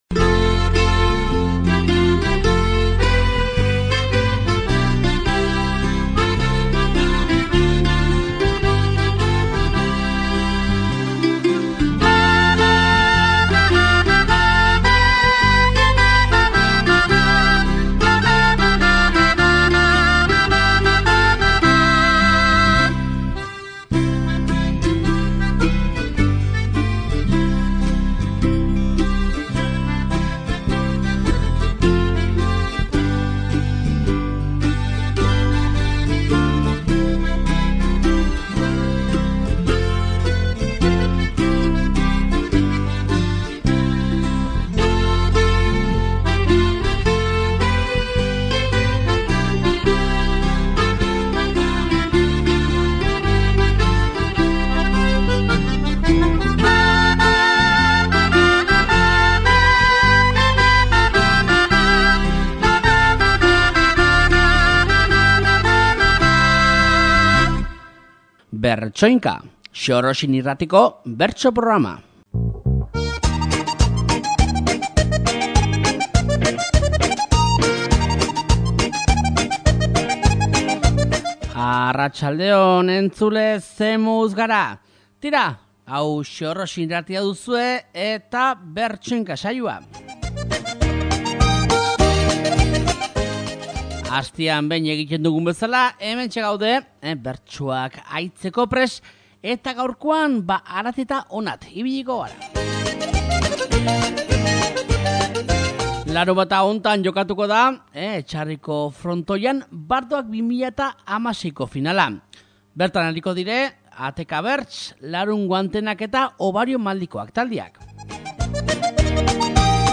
Bardoak 2016ko bigarren faseko bertsoak entzuteko, Leitza, Iruñea, Igantzi, Bera eta Etxarri Aranatzen barna ibiliko gara aste honetako Bertsoinka saioan